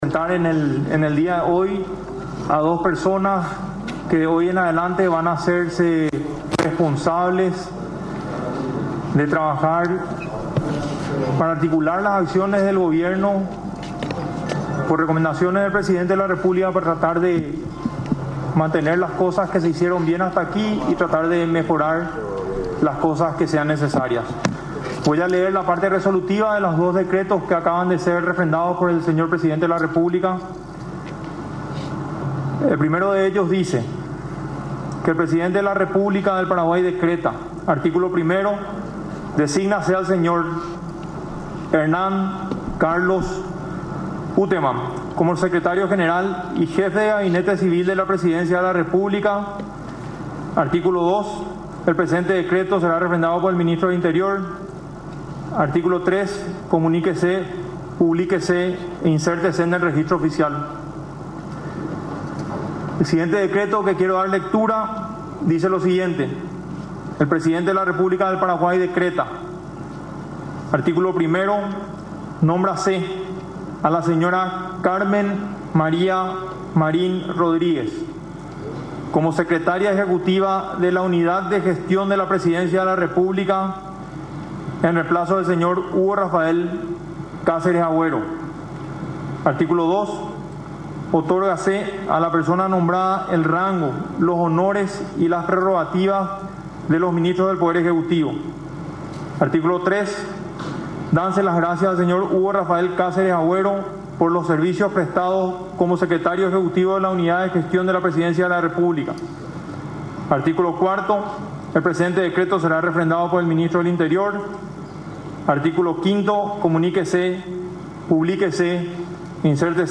Los cambios fueron presentados en conferencia de prensa por Juan Manuel Brunetti, titular del Ministerio de Información y Comunicación (MITIC).
21-CONFERENCIA-PRESIDENCIA.mp3